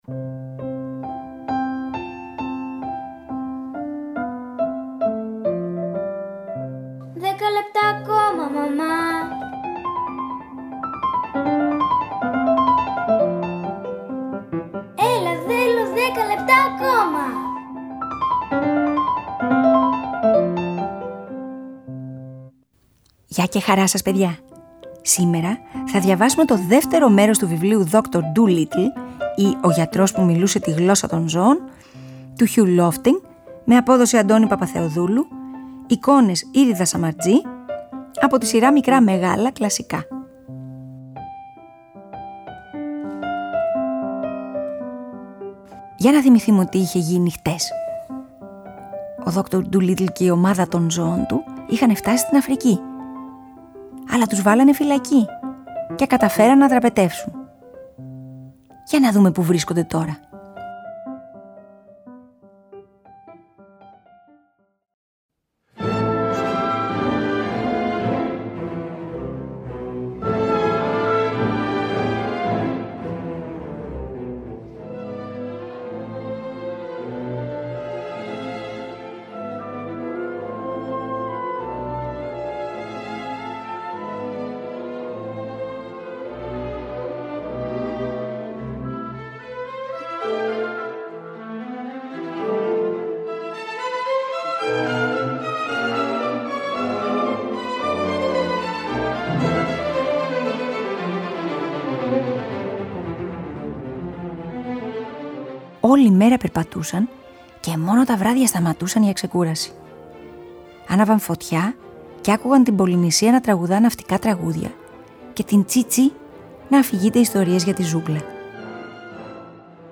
Ντουλίτλ ή ο γιατρός που μιλούσε τη γλώσσα των ζώων» του Αντώνης Παπαθεοδούλου – Antonis Papatheodoulou , με εικόνες Iris Samartzi illustrator , από τις ΕΚΔΟΣΕΙΣ ΠΑΠΑΔΟΠΟΥΛΟΣ Θα το διαβάσουμε σε δύο μέρη.